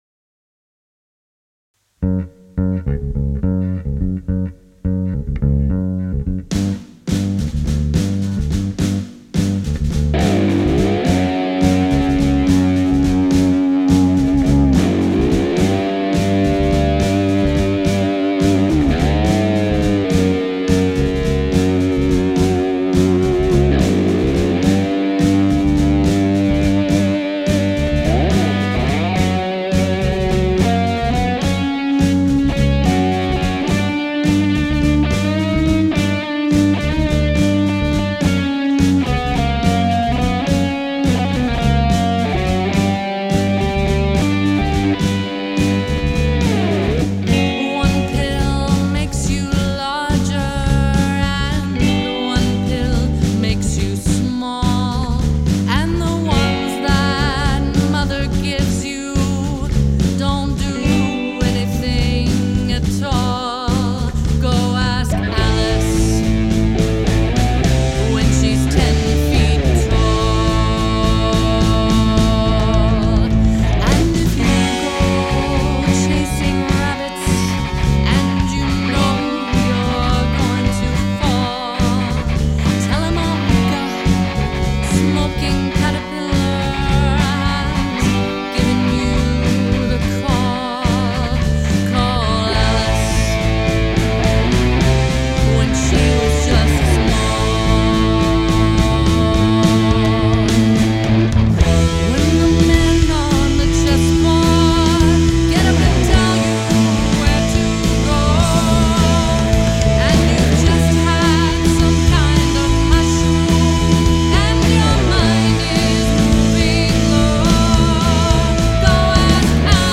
I did all the guitar parts
Definitely captures the haunting feel of the original.
Awesome mixing and playing.